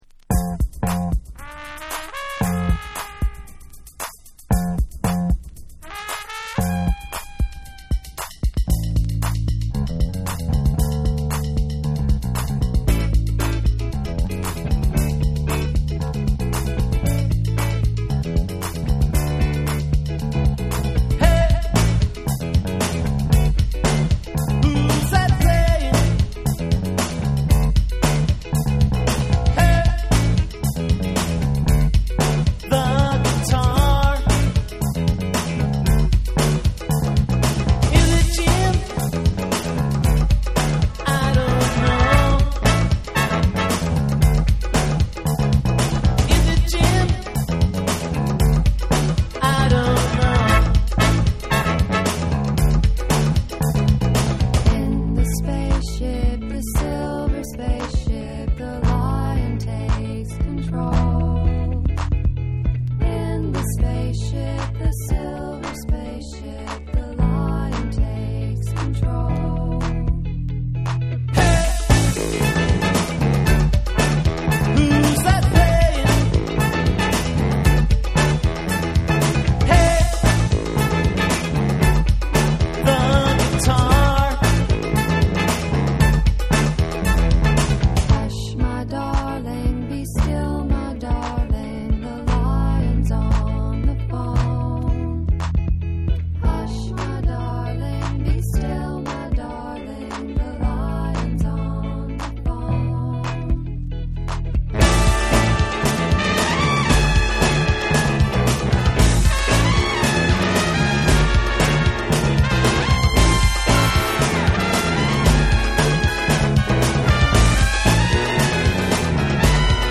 カントリーシンガー
NEW WAVE & ROCK